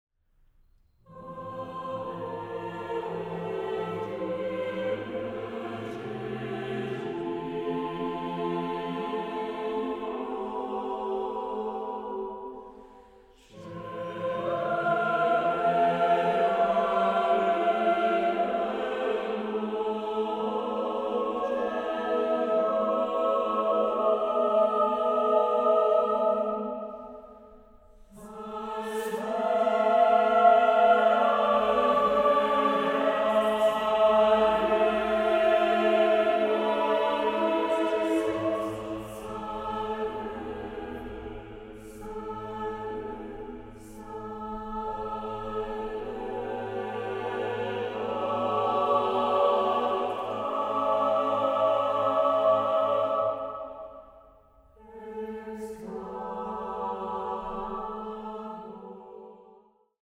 Chamber choir